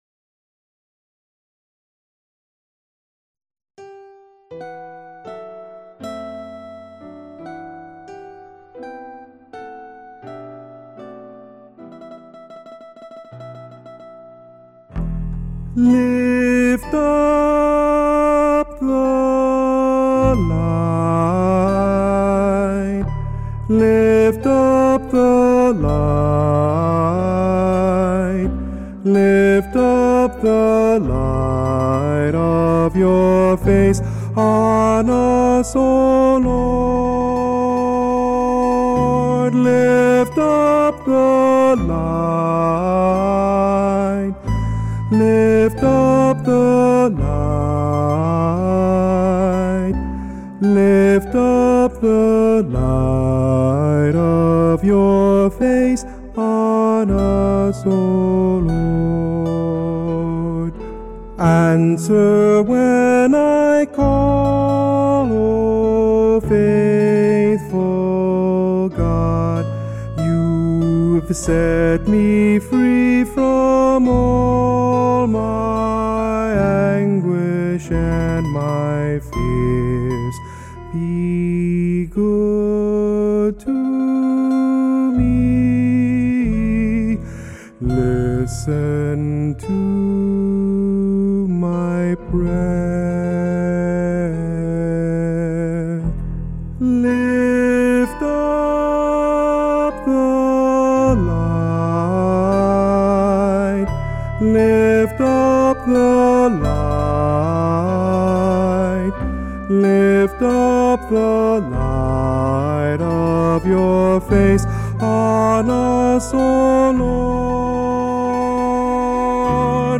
[Kundiman]